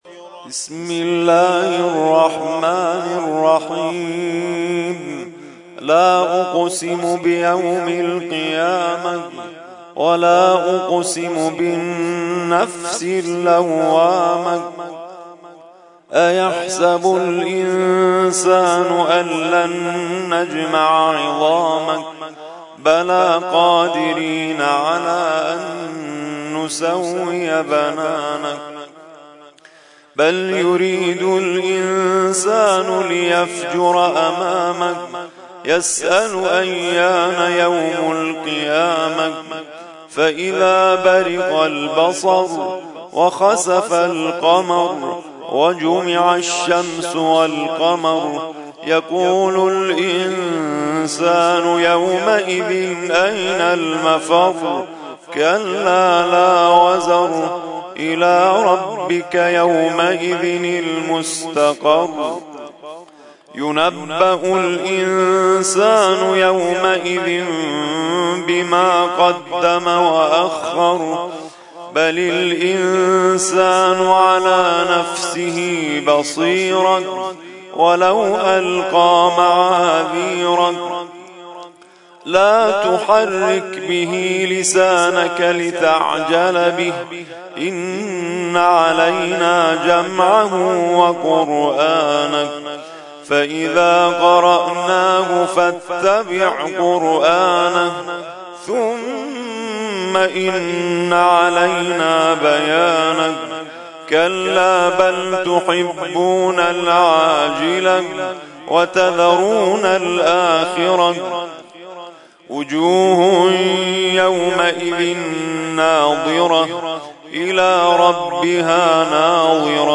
ترتیل خوانی جزء ۲۹ قرآن کریم در سال ۱۳۹۳